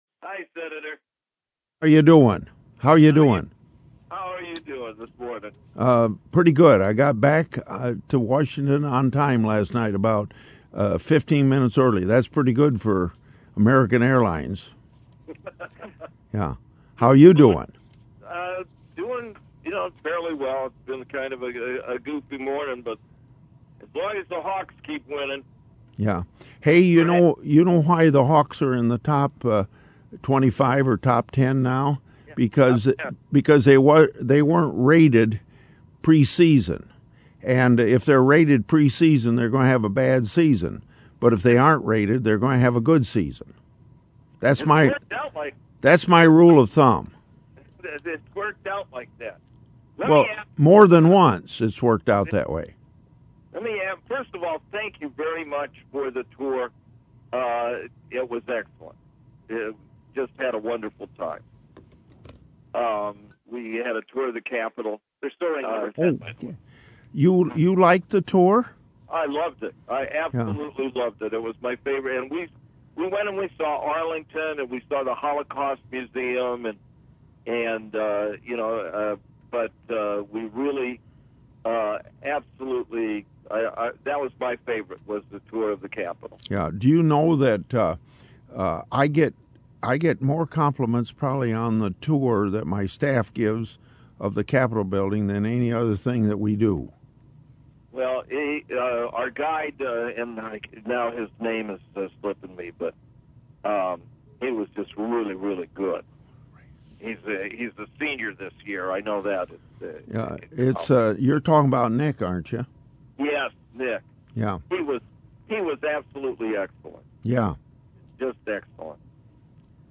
Grassley Live on KCJJ